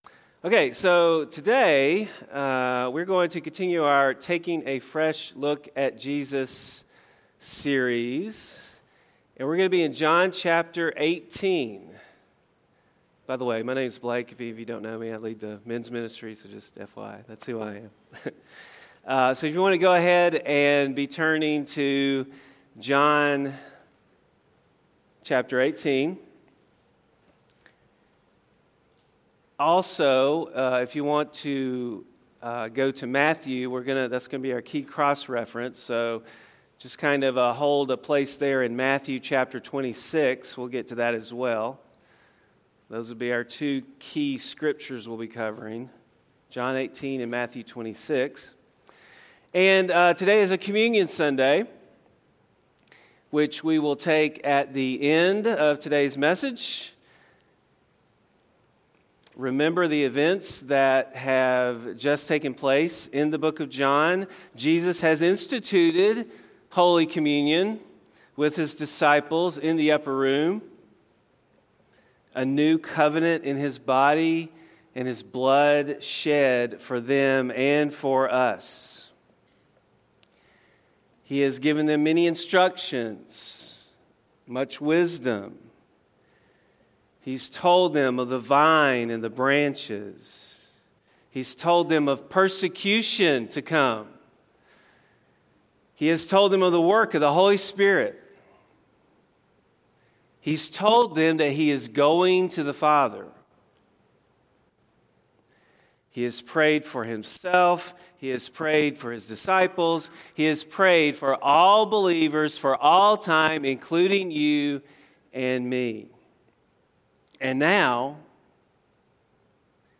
A message from the series "Ironworks."